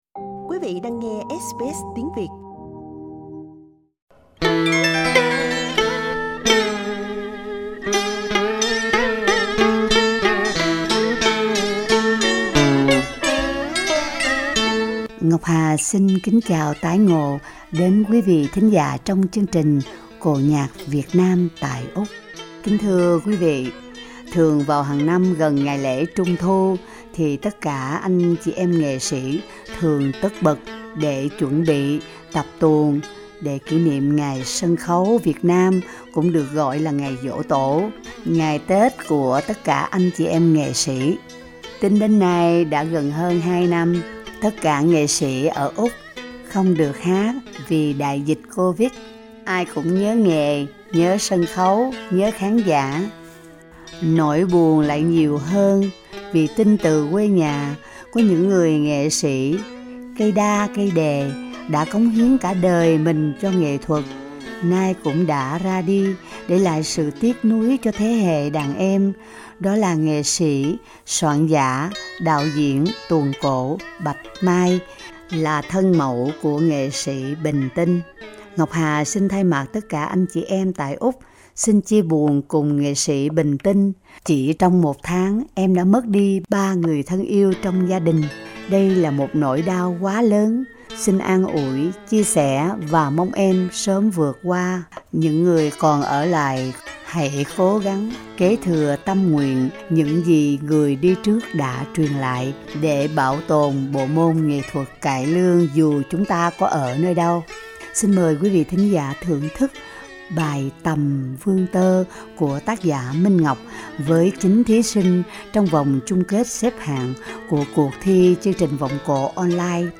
Dù có ở nơi đâu tất cả anh chị em vẫn một lòng chung sức góp lời ca tiếng hát để mong giữ gìn bộ môn nghệ thuật truyền thống Cải Lương qua chủ đề Sân khấu vang mãi khúc ca phối hợp cùng 9 thí sinh và ban giám khảo.
Sân khấu vang mãi khúc ca với 9 thí sinh Source: Supplied